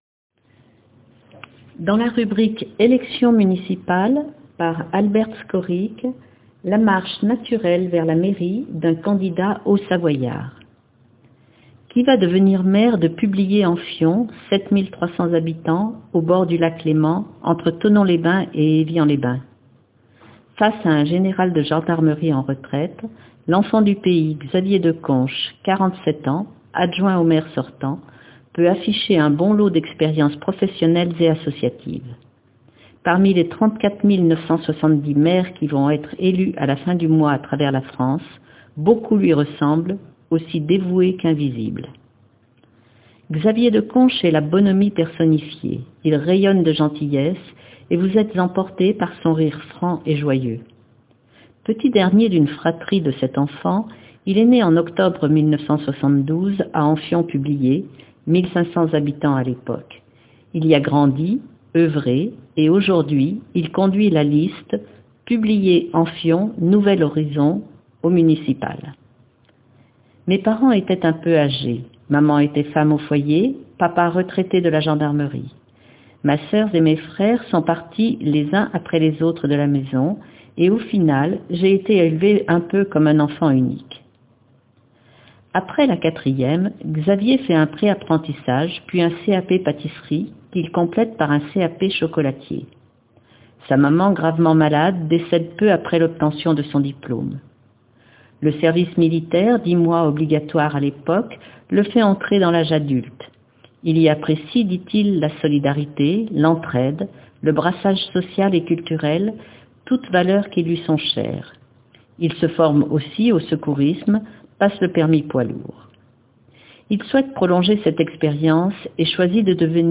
Xavier D.mp3 (4.59 Mo) Xavier Deconche est la bonhommie personnifiée, il rayonne de gentillesse et vous êtes emporté par son rire franc et joyeux.